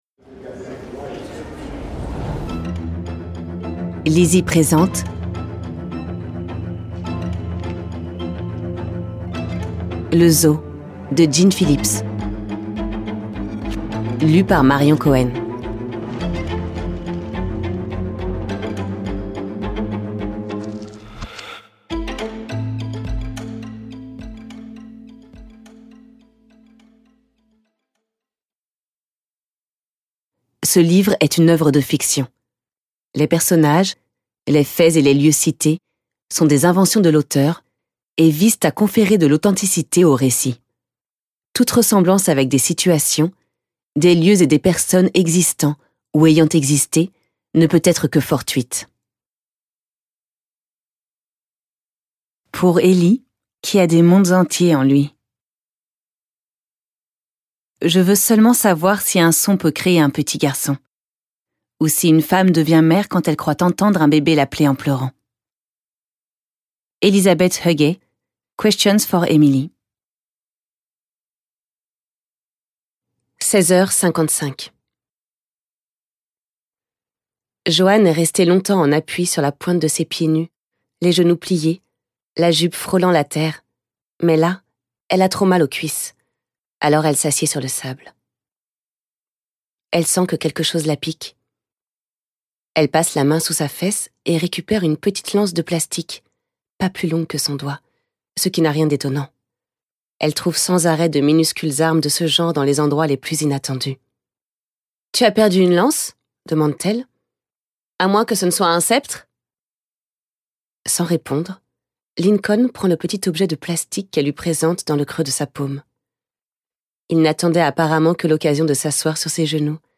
Extrait gratuit - Le Zoo de Gin PHILLIPS